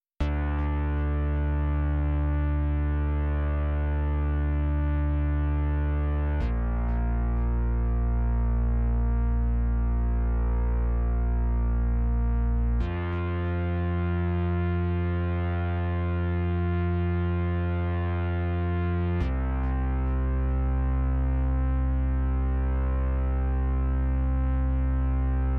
描述：CMINOR的合唱团循环；希望你觉得这个有用。
标签： 150 bpm Cinematic Loops Choir Loops 4.31 MB wav Key : Cm Audition
声道立体声